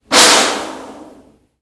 MachinegunFire4
描述：A machine gun sound, made in Soundforge with FM synthesis.
标签： gunshot machinegun foley
声道立体声